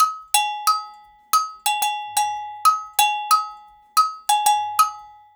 90 AGOGO03.wav